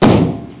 BANGSHT.mp3